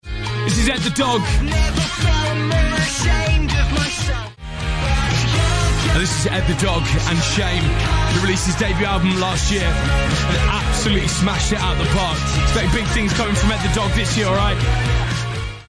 エド・ザ・ドッグ
BBC Radio 1 Jack Saundersの発音